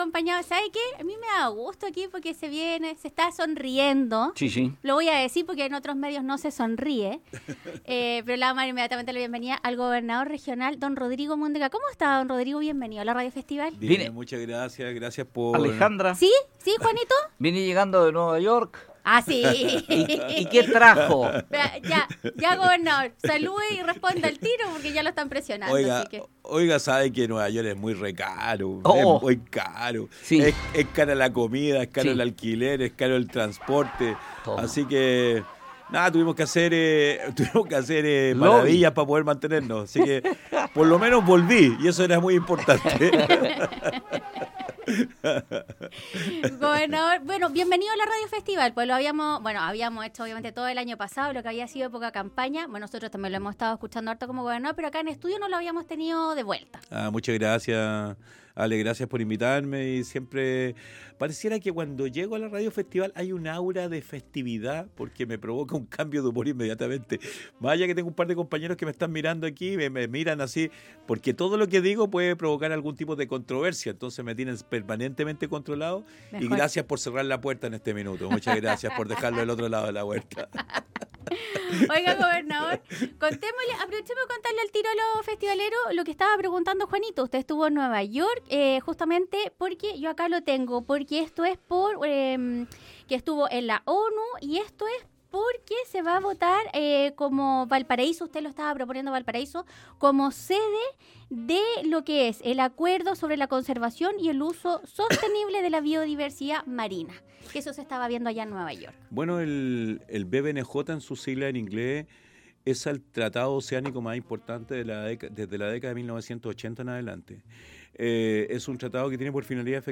El Gobernador Rodrigo Mundaca estuvo en los estudios de Radio Festival donde realizó un breve resumen de los fondos de Vinculación del 8%, el Viaje a Nueva York, el aniversario del Gore y lo que viene a futuro.